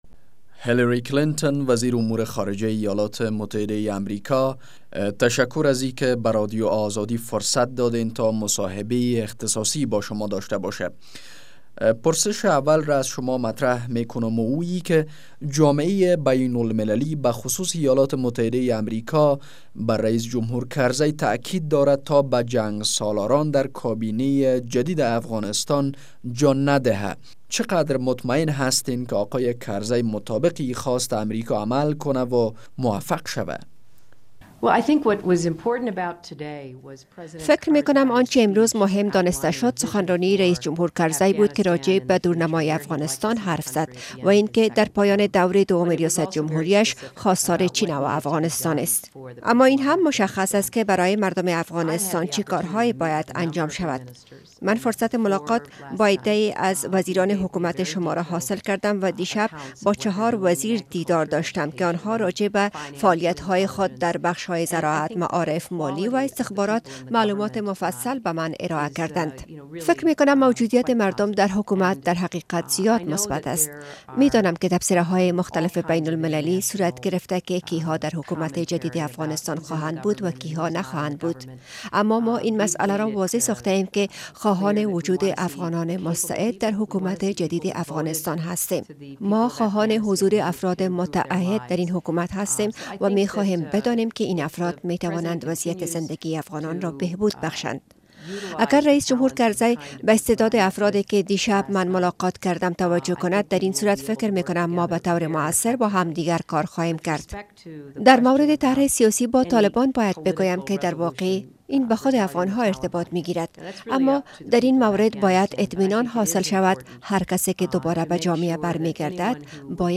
مصاحبه با هیلاری کلنتن وزیر خارجهء ایالات متحده امریکا